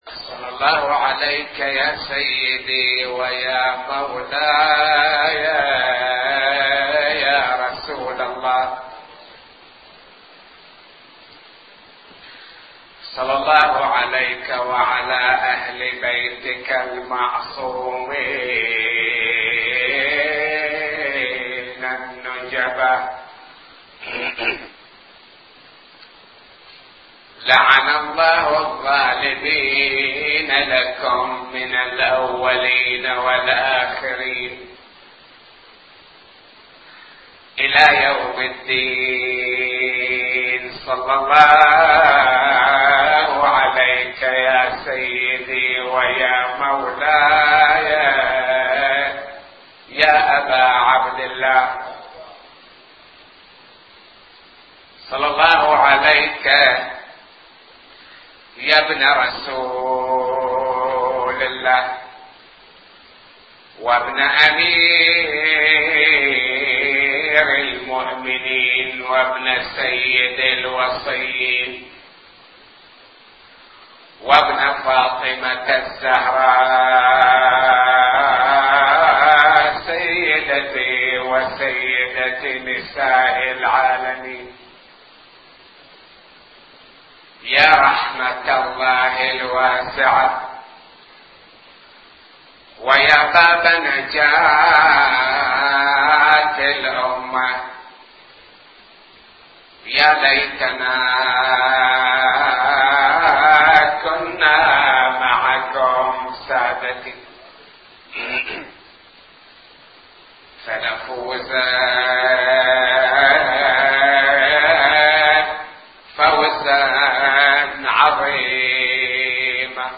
نواعي وأبيات حسينية – 7